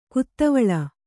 ♪ kuttavaḷa